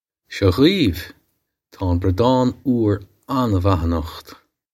Pronunciation for how to say
Shaw gheev. Taw on brodd-awn oor onna-wvah a-nukht.
This is an approximate phonetic pronunciation of the phrase.